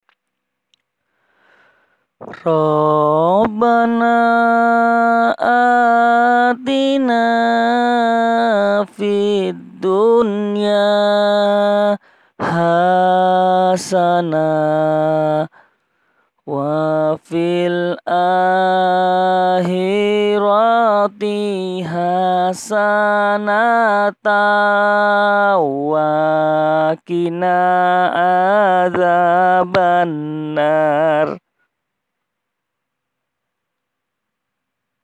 Maaf kalau suaranya jelek, ini asli saya rekam sendiri bacaan pujian yang biasanya saya pakai.
Mohon maaf sebelumnya jikalau suara saya jelek :).